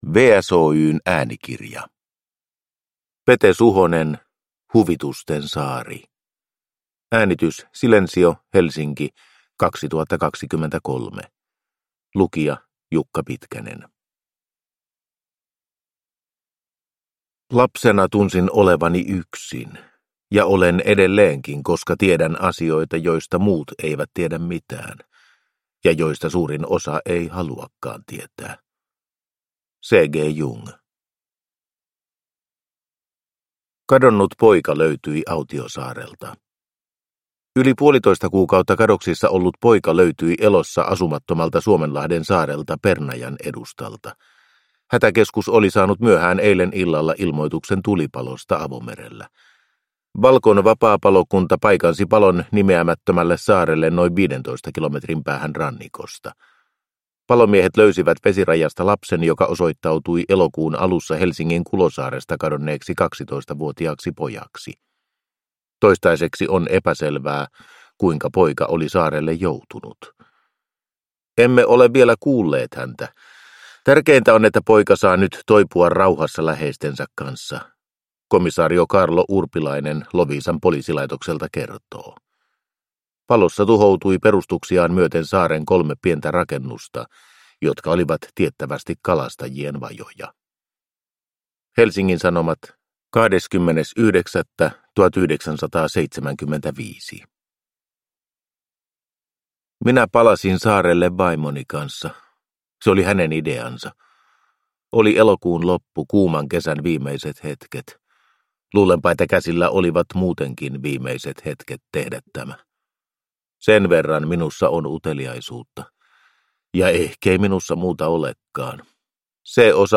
Huvitusten saari (ljudbok) av Pete Suhonen